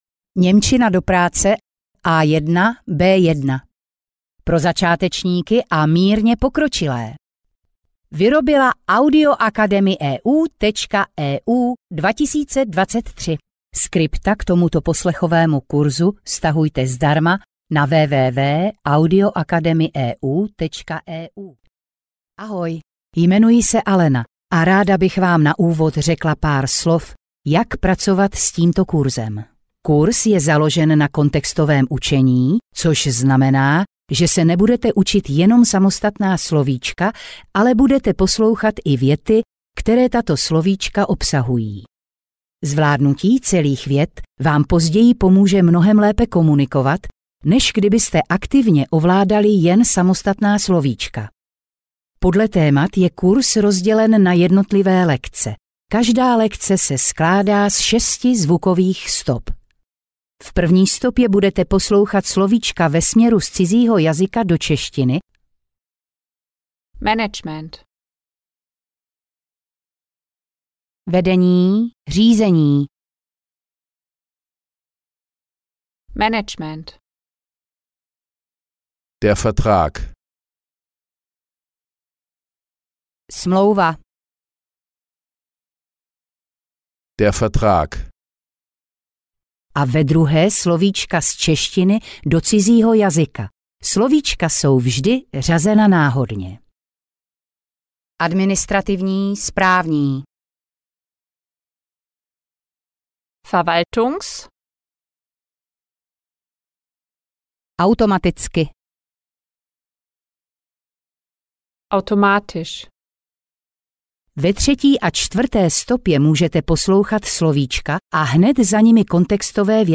Ukázka z knihy
Dále máte k dispozici slovíčko následované příkladovou větou, opět v obou variantách překladu (stopa 3 a 4).
Jakmile budete zvládat překládat věty z němčiny do češtiny (lekce 6) v časové pauze před českým překladem, tak jste vyhráli.